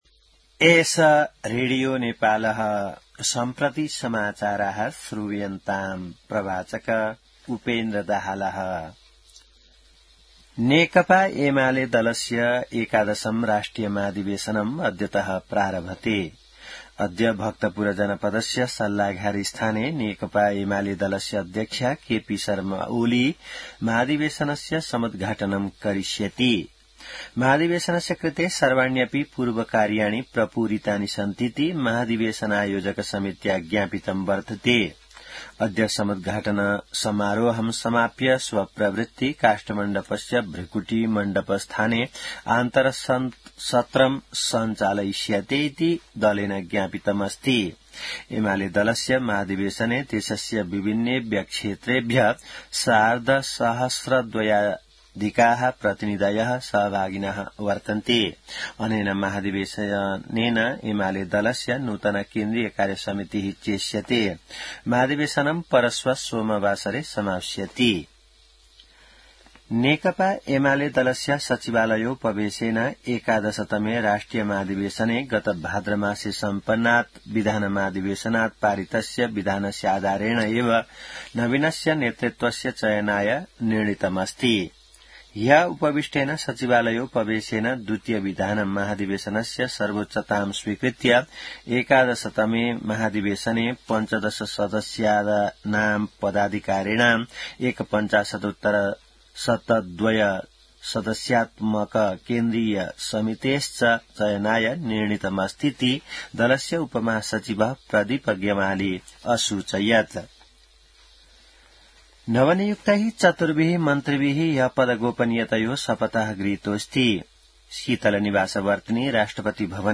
संस्कृत समाचार : २७ मंसिर , २०८२